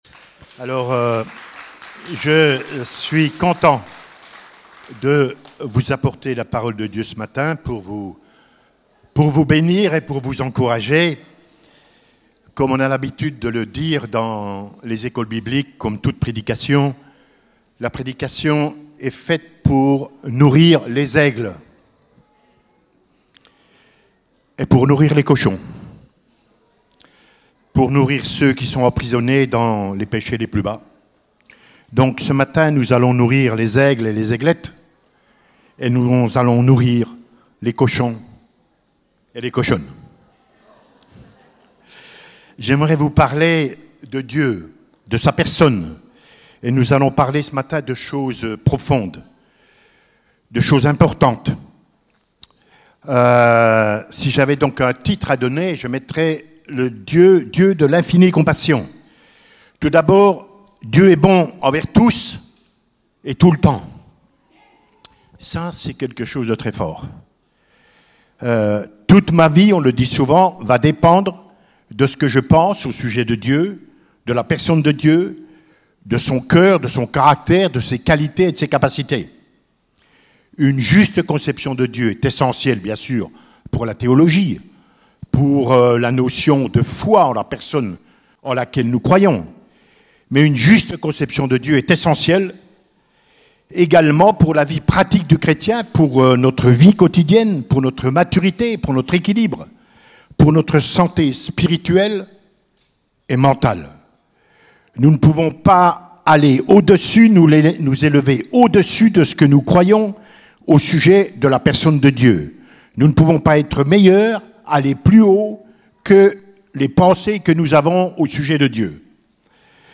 Type De Service: Prédication Culte